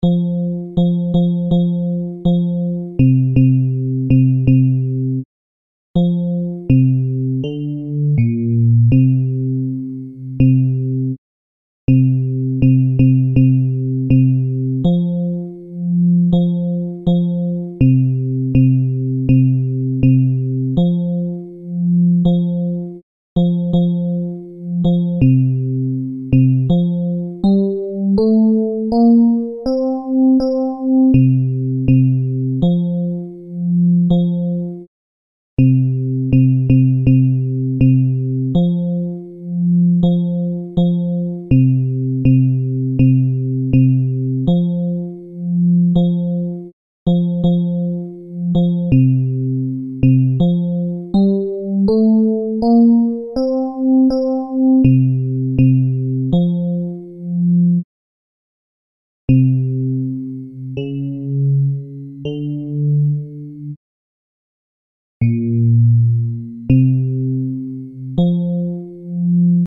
Ténors
due_pupille_tenors.MP3